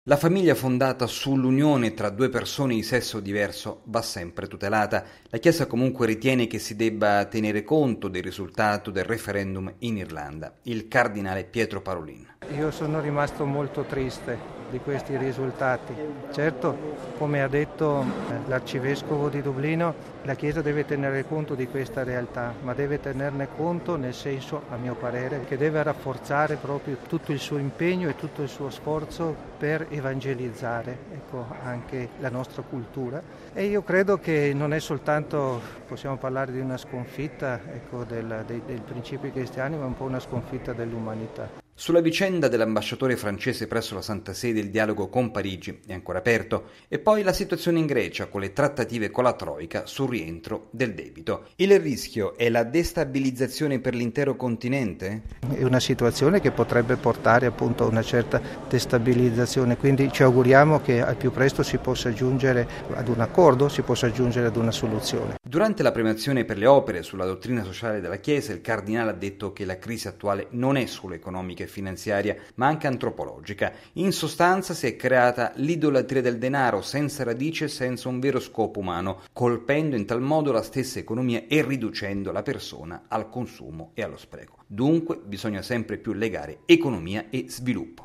Il segretario di Stato vaticano, il card. Parolin, ha definito in questo modo il risultato del referendum sulle nozze gay in Irlanda. Il cardinale è intervenuto ieri sera al premio per la Dottrina Sociale della Chiesa, bandito dalla Fondazione Centesimus Annus.